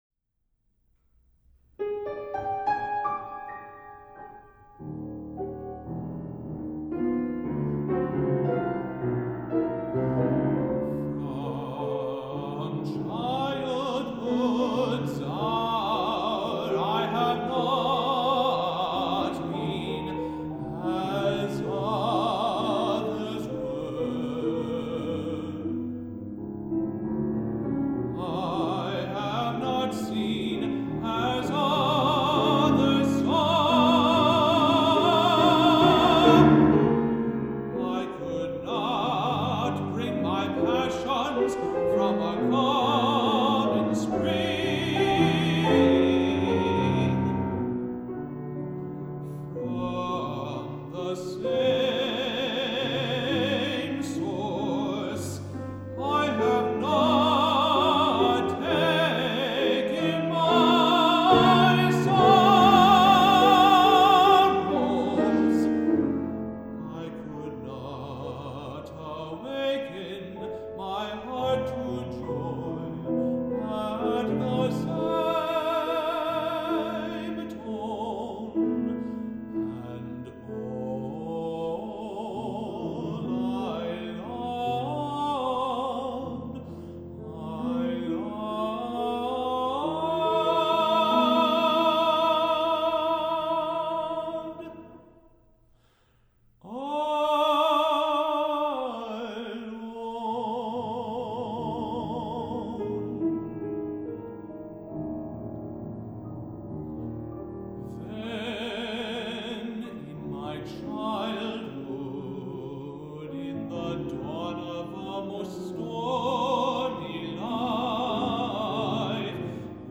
Three Songs for High Voice and Piano
tenor
piano